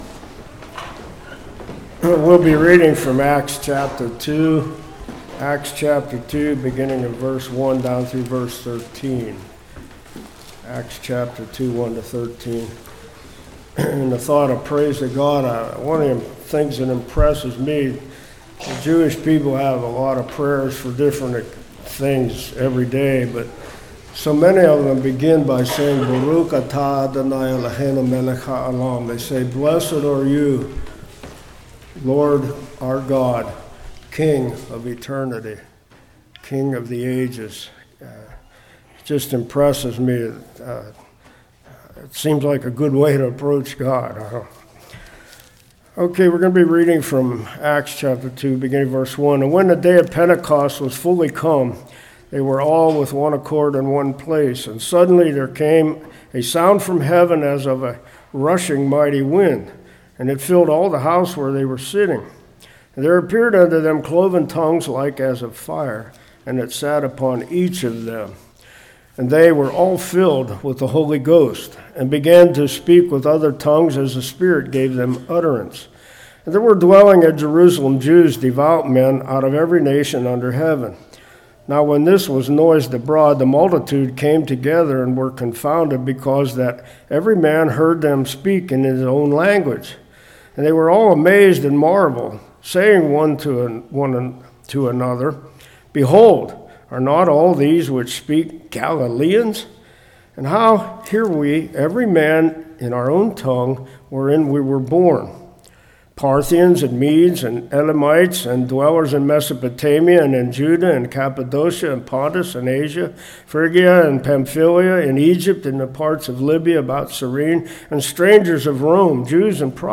Acts 2:1-8 Service Type: Morning Be of one mind.